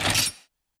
MH - Menu Click 2 (MH3U).wav